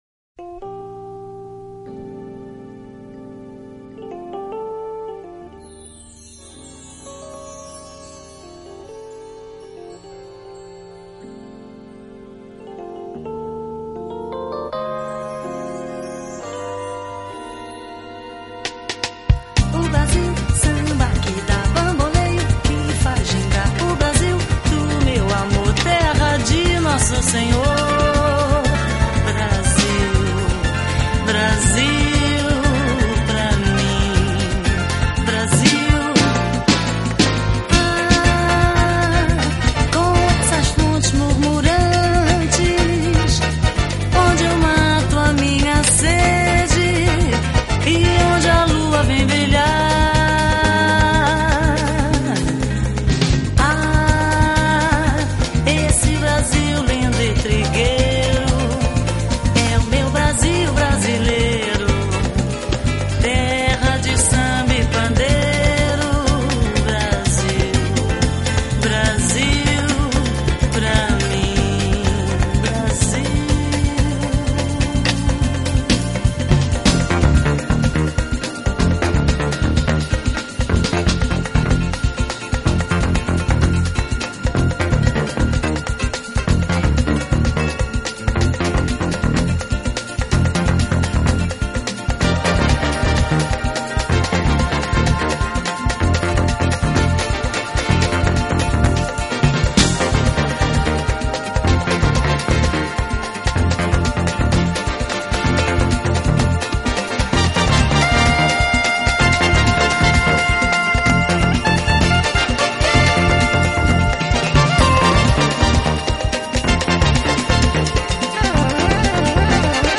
【Jazz & Fusion】
The sound is a wonderful blend of Japanese
and alto sax
The feel is almost like some of the best Brazilian-
that's never cheesy, and a groove that's always breezy.